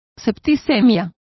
Complete with pronunciation of the translation of septicaemias.